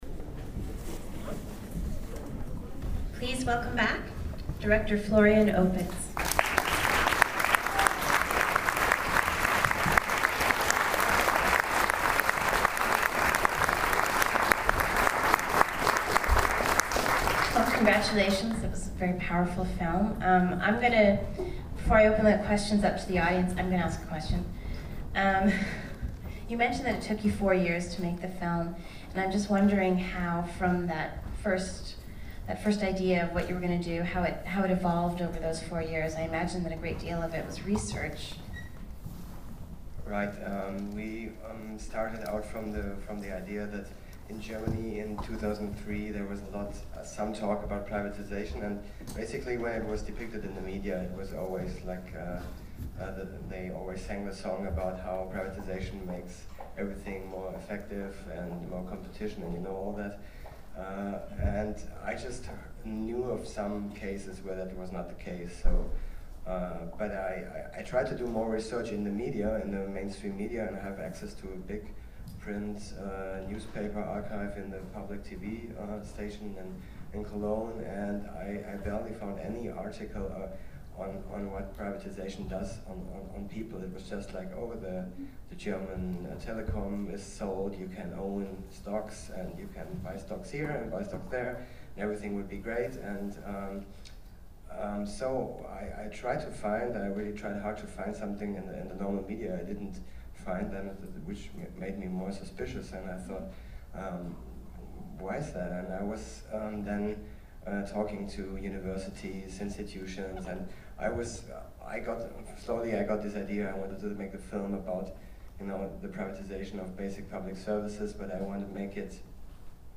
bigsellout_qa.mp3